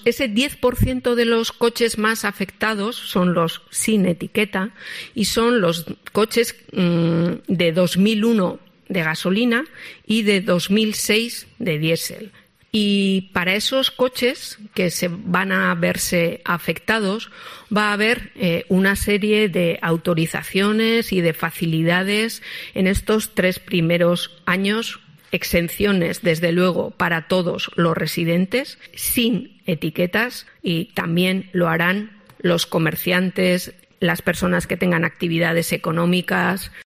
Marisol Garmendia, edil de Ecología del Ayuntamiento de San Sebastián